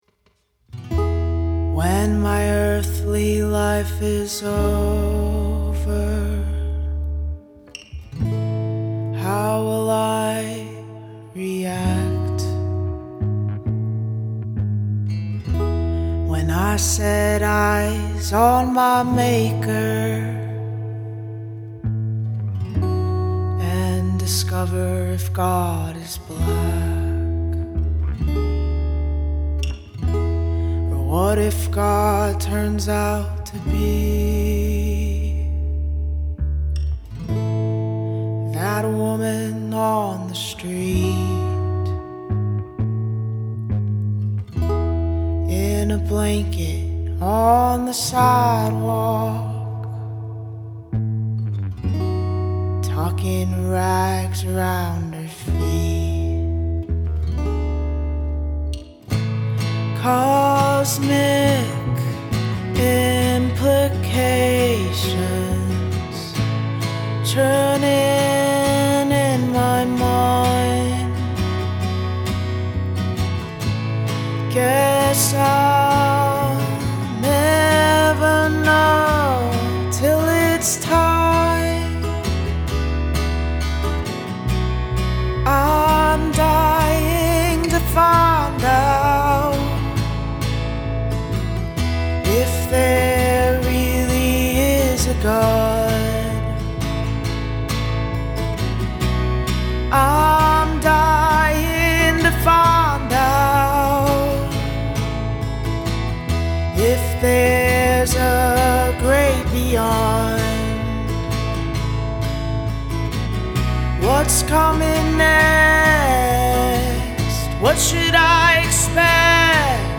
Guitar, Vox, Bass
A beautiful and thought-provoking song, delivered with such empathic and compassionate vocals. And +1 on the way the bass adds so much heft to the arrangement, too.
That bass in the first 2 slow verses really captured my attention ~ the low vibration resonates into the soul perhaps?